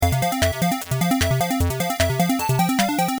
アイキャッチやジングルに使えそうな短いフレーズ
ゲームクリア_2 ステージクリア用BGM（ループ）